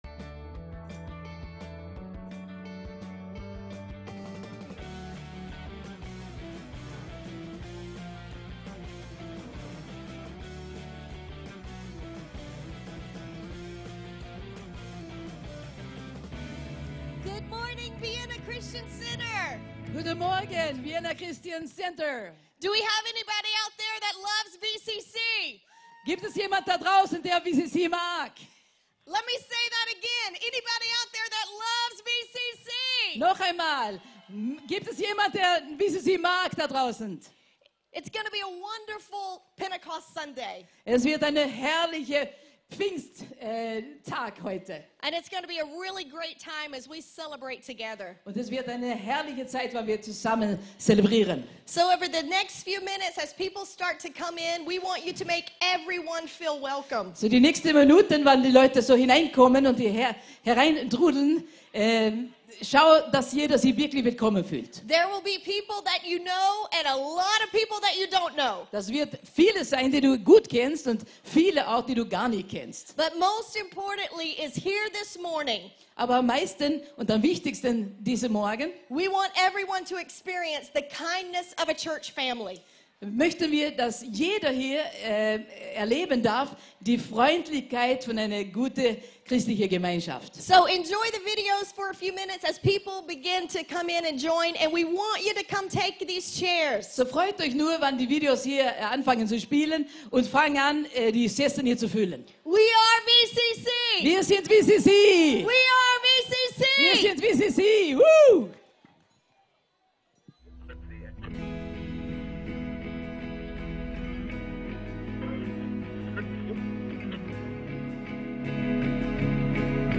FRIEND DAY CELEBRATION ( Freund Tag Celebration ) ~ VCC JesusZentrum Gottesdienste (audio) Podcast